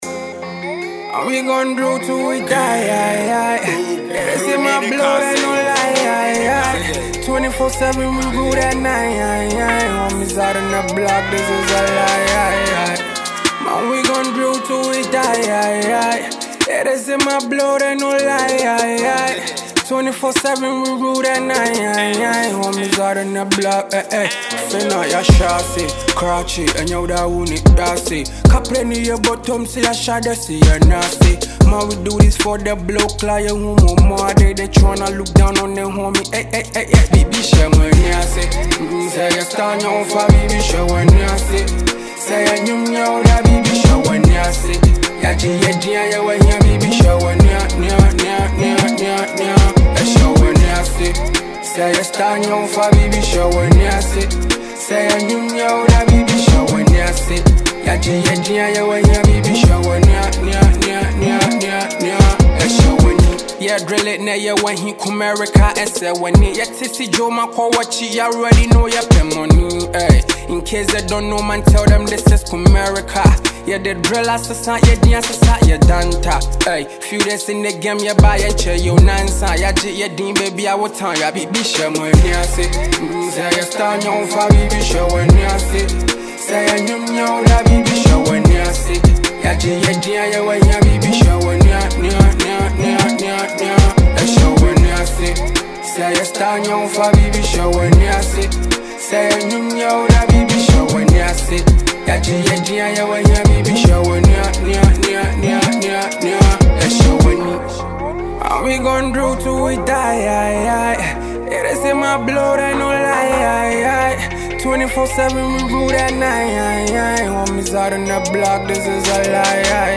hard-hitting street anthem